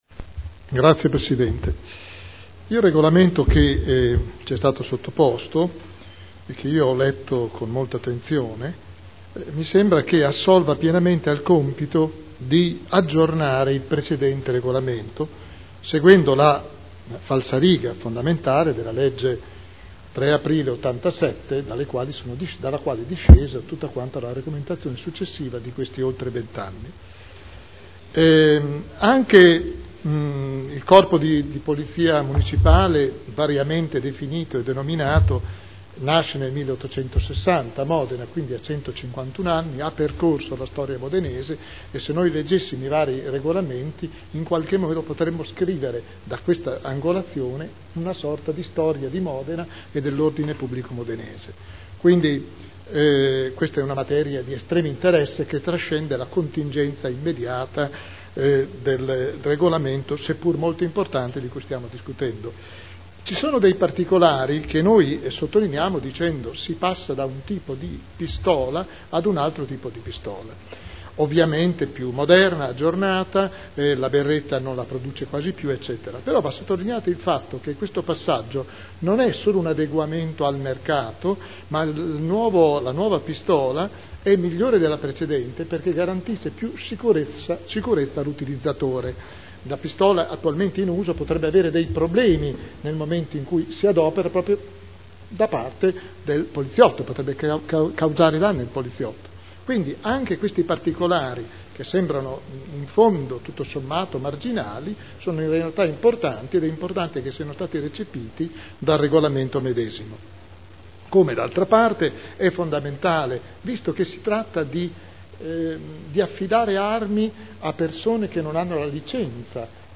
Seduta del 05/12/2011. Dibattito.